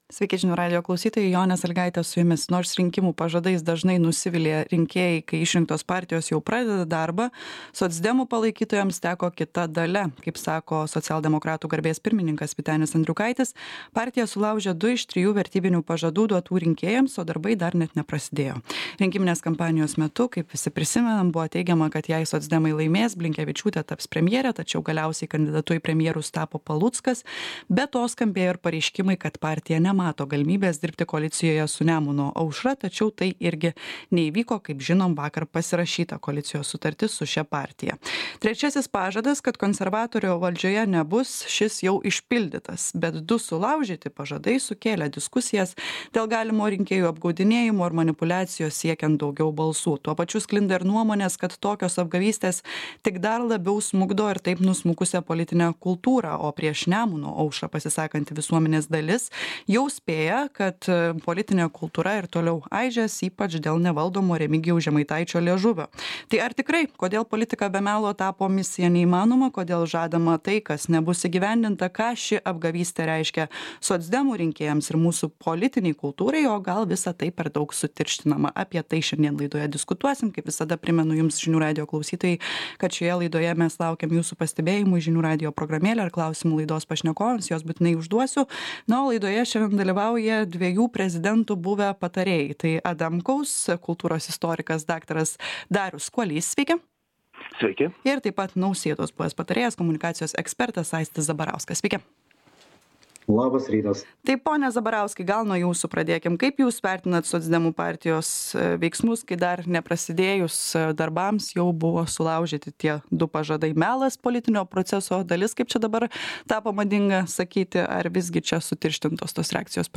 Laidoje dalyvauja kultūros istorikas dr.